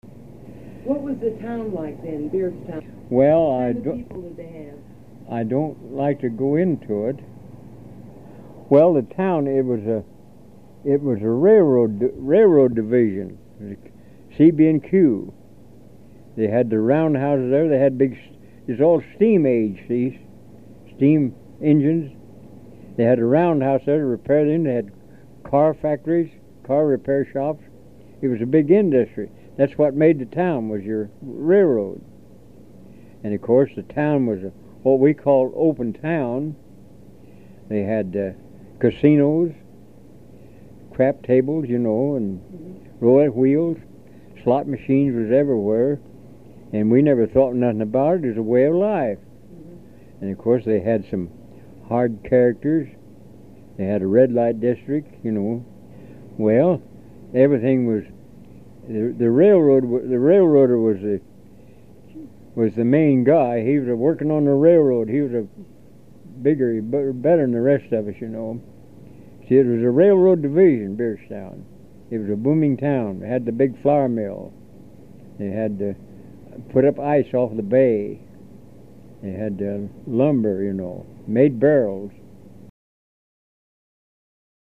HTR Oral History, 07/17/1